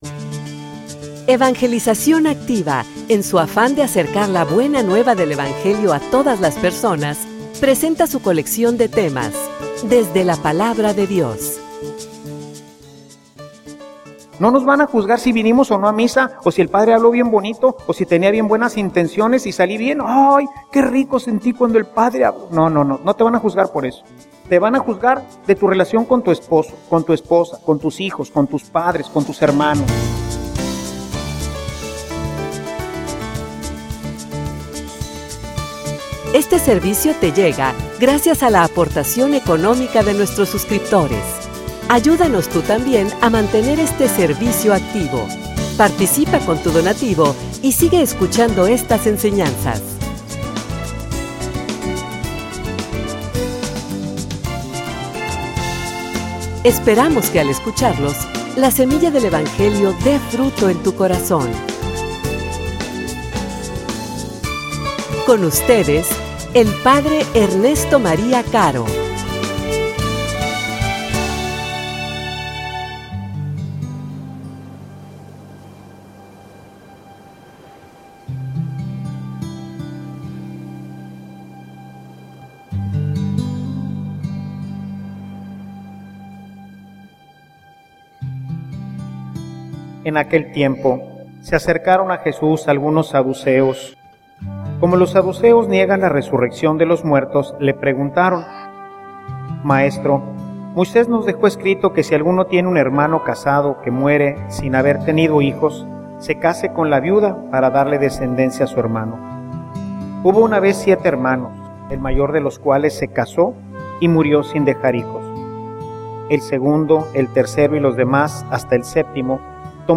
homilia_En_donde_despertare.mp3